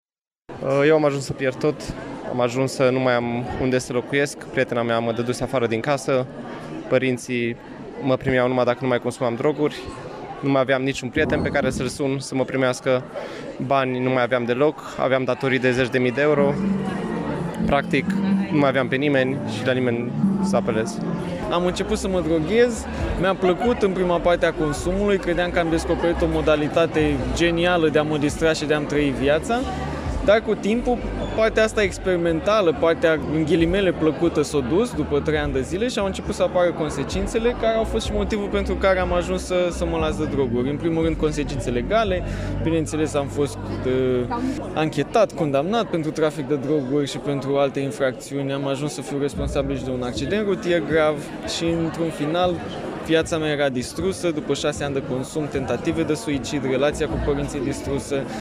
În cadrul evenimentului de la Iași, doi consumatori de droguri și-au prezentat experiența de viață, faptul că după 2-3 ani, ajunseseră dependenți și, ulterior, au fost anchetați și condamnați pentru trafic de droguri.